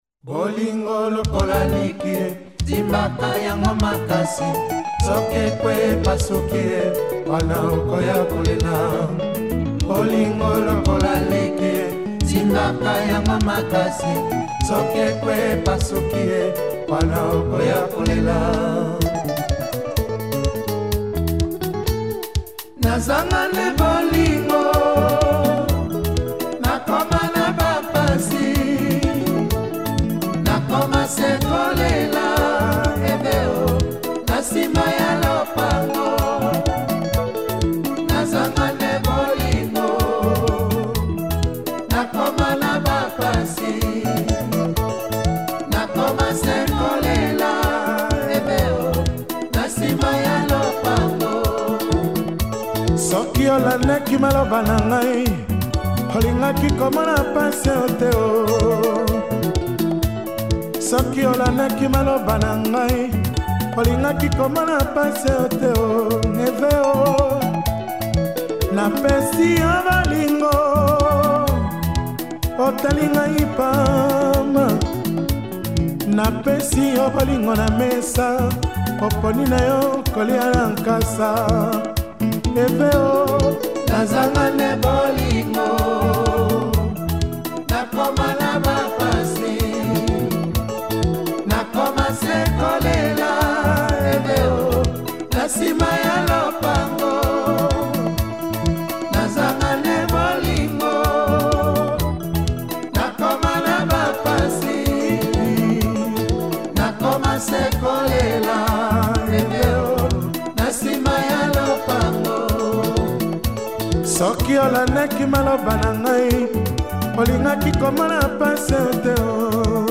soukous